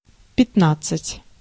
Ääntäminen
Ääntäminen France Tuntematon aksentti: IPA: /kɛ̃z/ Haettu sana löytyi näillä lähdekielillä: ranska Käännös Ääninäyte Substantiivit 1. пятнадцать (pjatnadtsat) Suku: m .